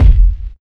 KICK RUGGED II.wav